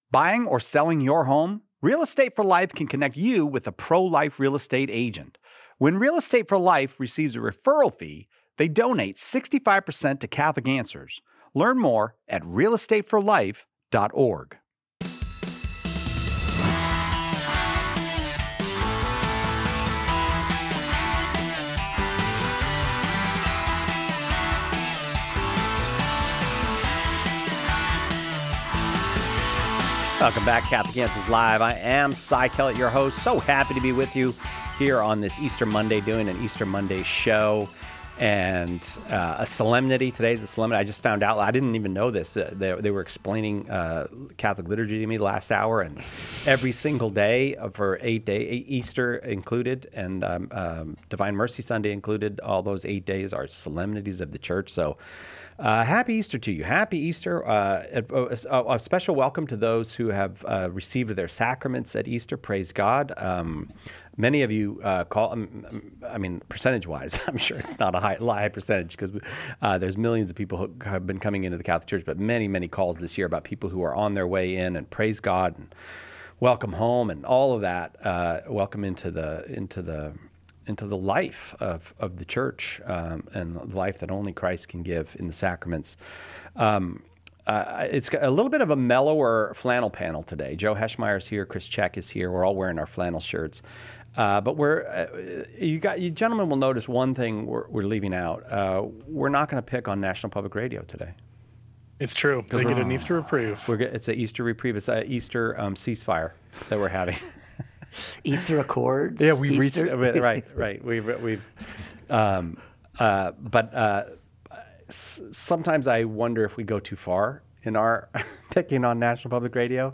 The panel also weighs in on the alarming religious persecution in Nicaragua, highlighting how faithful Catholics can advocate for the oppressed through prayer and action.